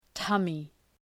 {‘tʌmı}